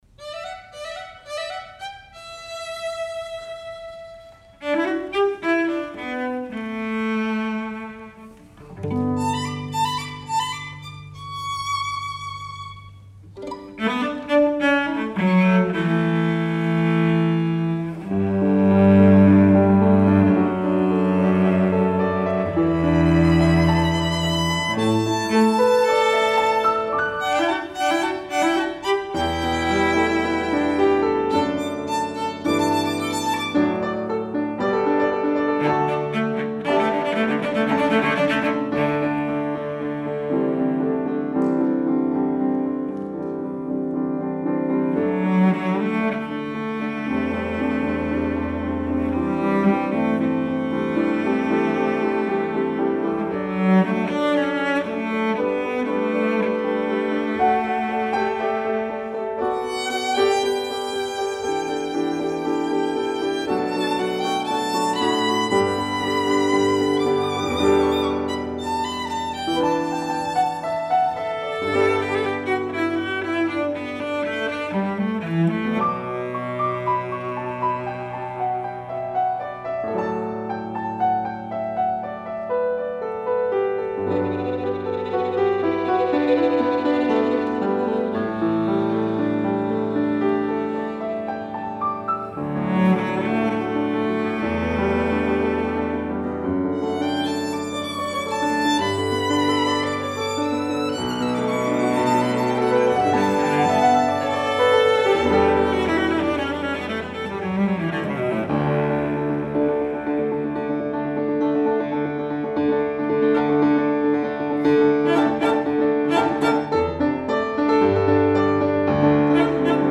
for Piano Trio (2015)